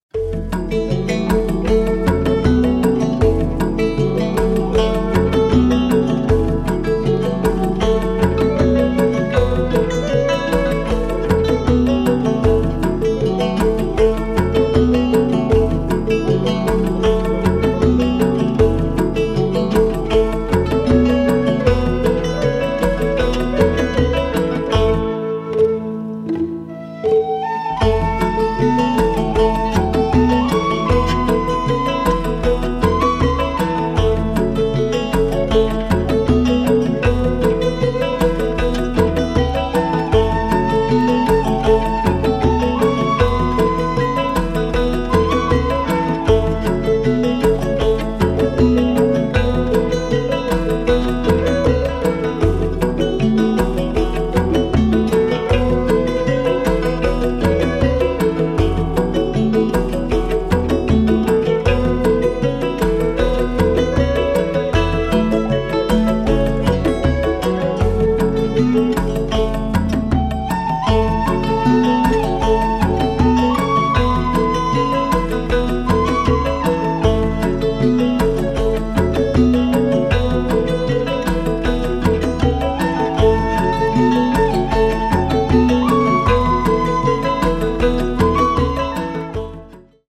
Style:World / Electronic / Other